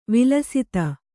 ♪ vilasita